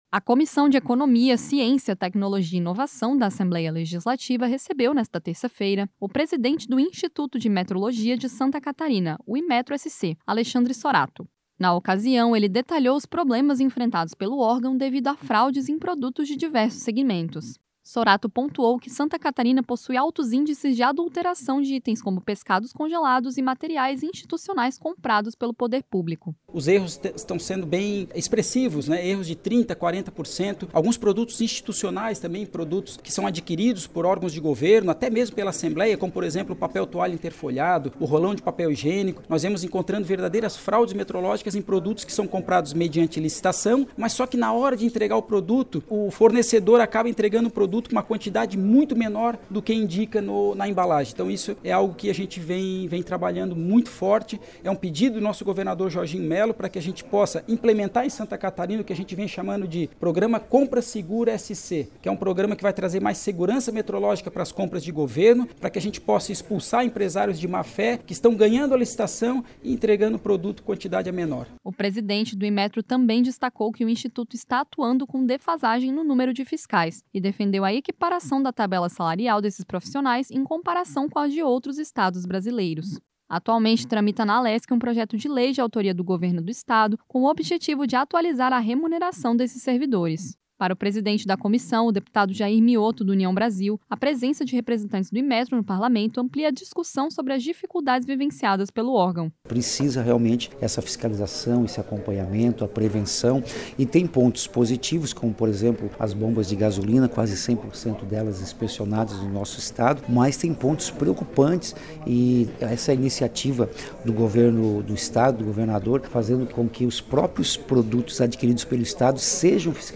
Entrevistas com:
- Alexandre Soratto, presidente do Instituto de Metrologia de Santa Catarina;
- deputado Jair Miotto (União Brasil), presidente da Comissão de Economia, Ciência, Tecnologia e Inovação da Alesc.